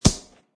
plasticgrass3.mp3